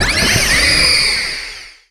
Cri de Tokopiyon dans Pokémon Soleil et Lune.